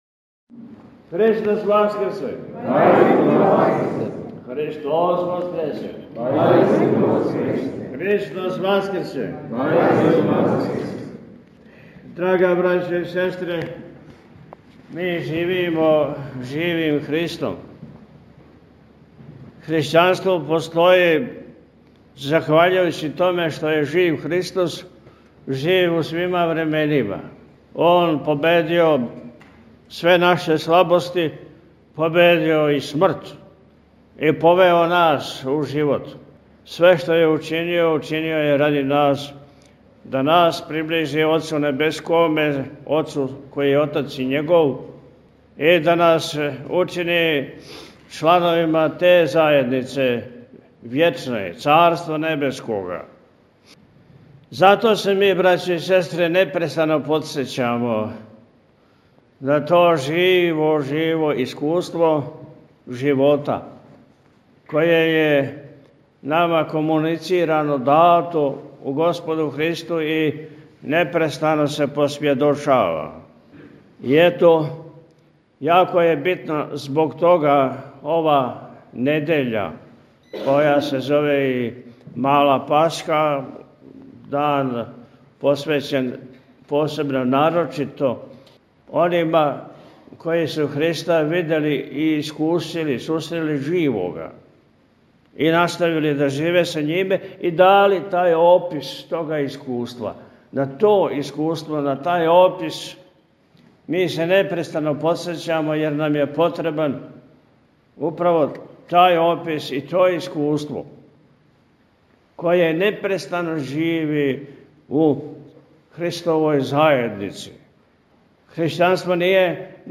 Његово Високопреосвештенство Архиепископ и Митрополит милешевски г. Атанасије служио је у Недељу другу – Томину, 19. априла 2026. године, Свету архијерејску Литургију у Саборном храму [...]
Беседу Митрополита Атанасија можете послушати овде: